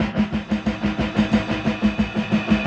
Index of /musicradar/rhythmic-inspiration-samples/90bpm
RI_DelayStack_90-04.wav